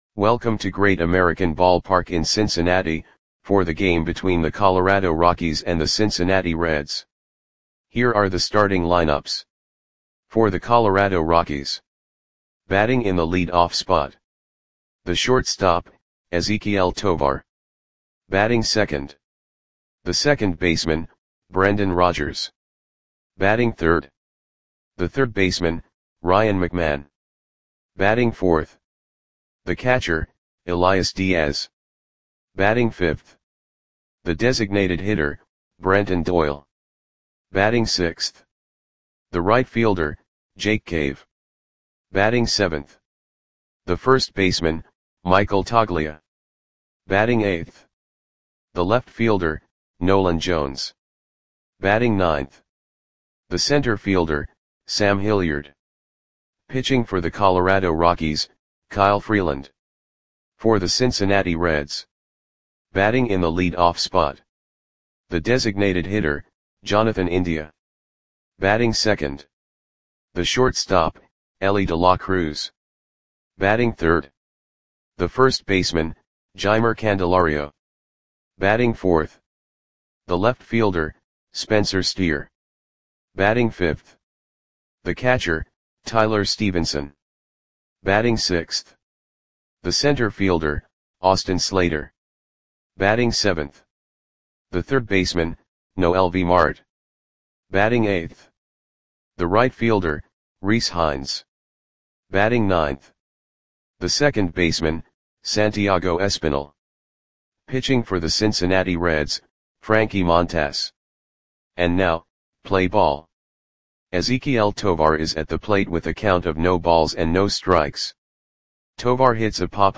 Click the button below to listen to the audio play-by-play.
Rockies 6 @ Reds 5 Great American BallparkJuly 10, 2024 (No Comments)